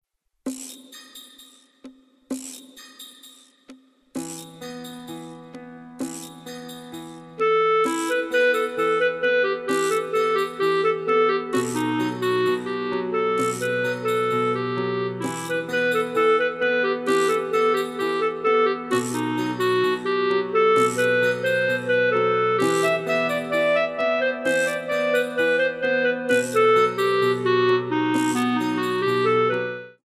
Piano Music for Modern Dance Class